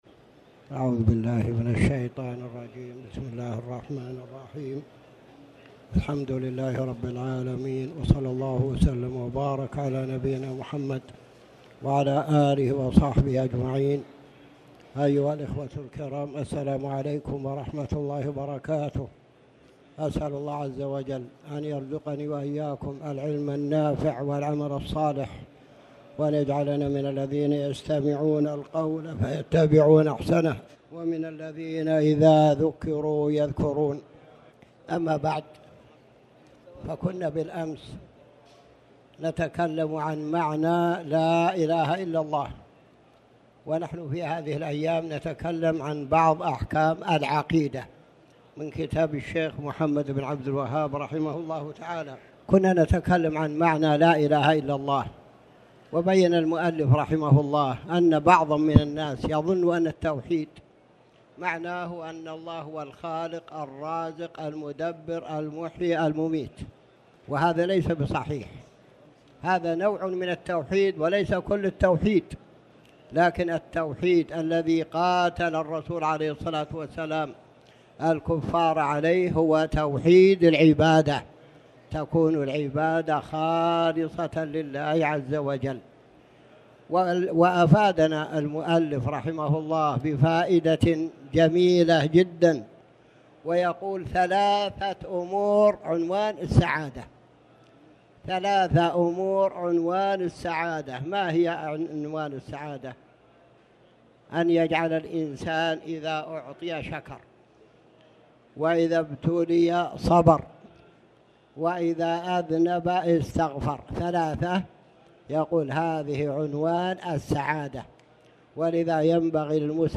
تاريخ النشر ١٩ جمادى الآخرة ١٤٣٩ هـ المكان: المسجد الحرام الشيخ